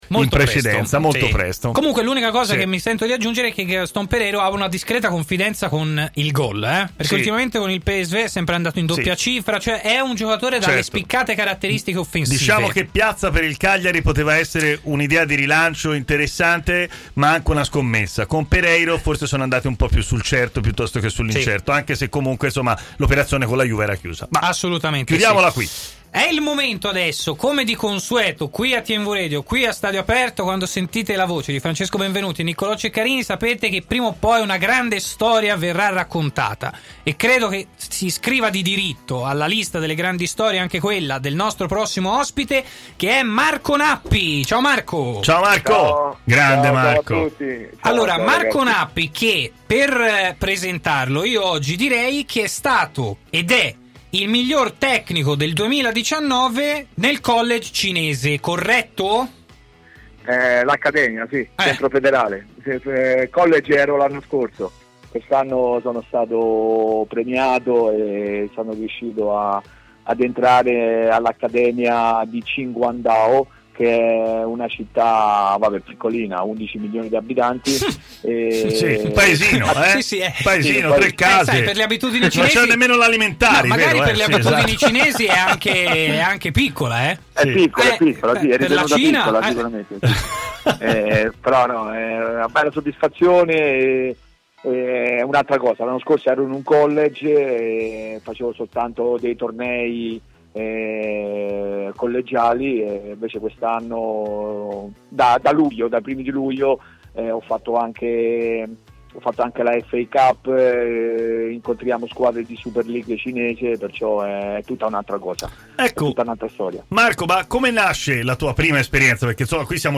ospite di TMW Radio durante 'Stadio Aperto':